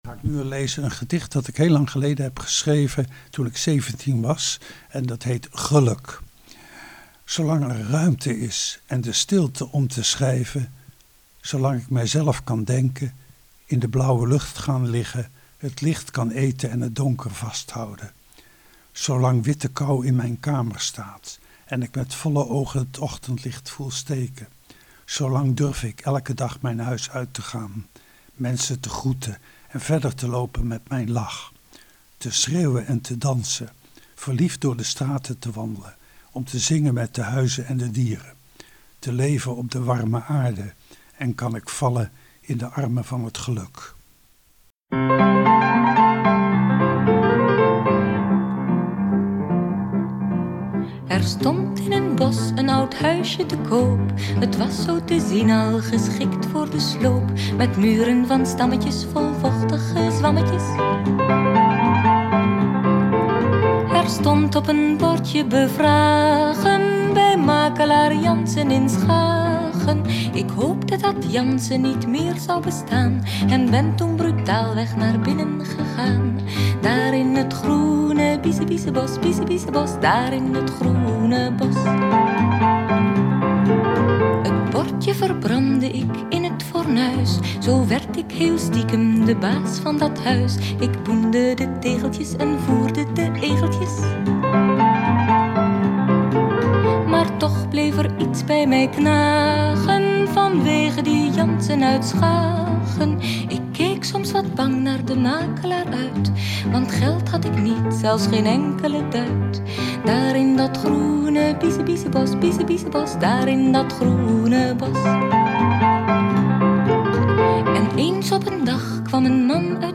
Gedicht